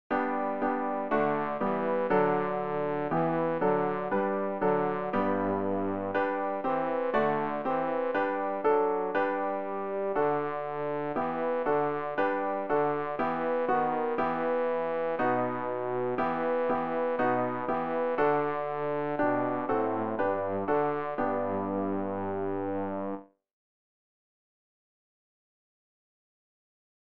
rg-690-jesu-geh-voran-auf-der-lebensbahn-bass.mp3